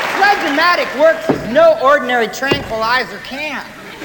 Category: Comedians   Right: Personal
Tags: Comedians Gallagher Smashing Watermelons Sledge-o-matic Prop comic